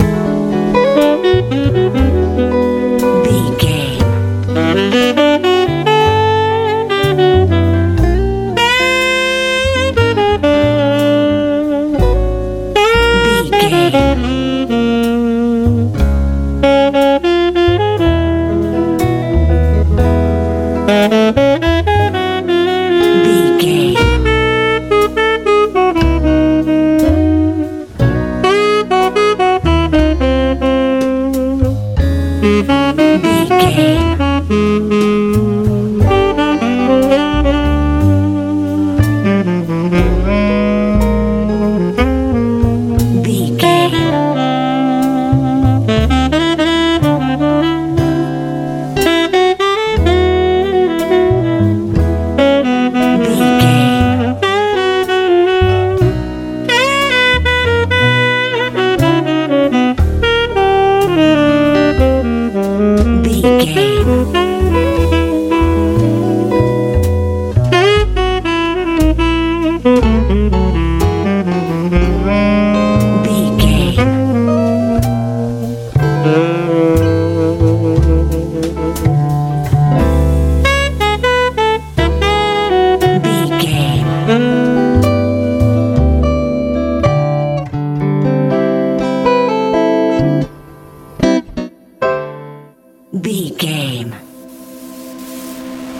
Ionian/Major
soothing
piano
horns
bass guitar
drums
romantic
calm